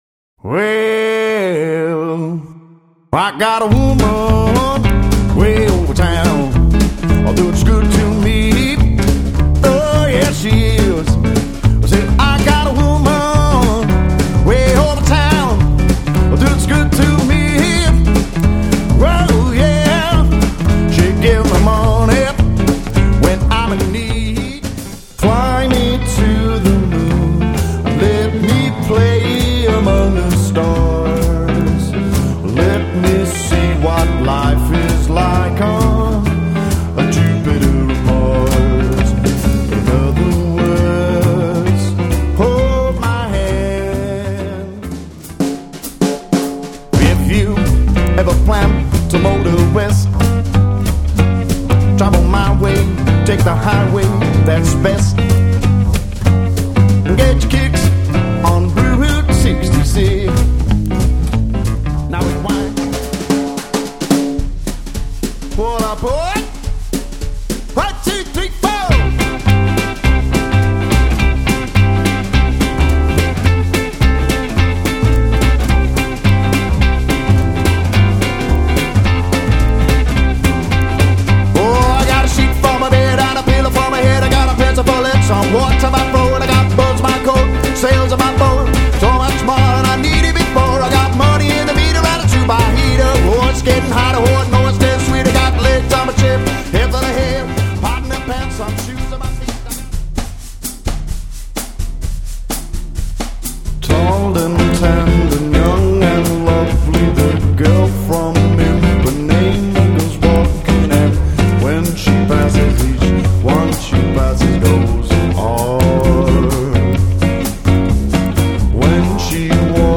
Jiving swing and toe-tapping jazz
guitar & vocals, double bass and drums